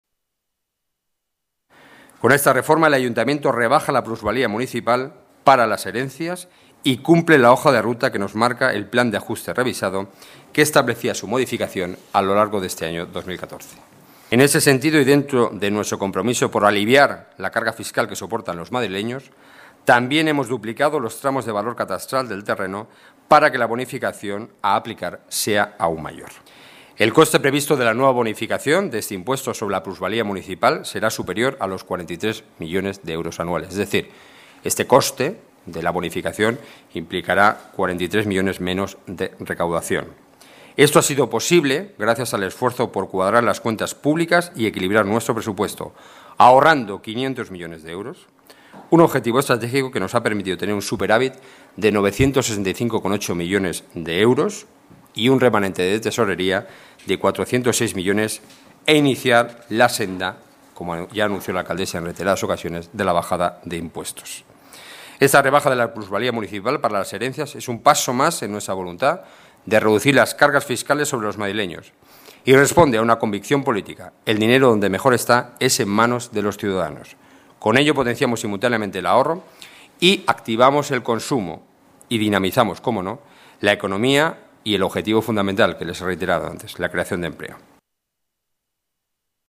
Nueva ventana:Declaraciones Enrique Núñez, portavoz del Gobierno: Novedades Plusvalía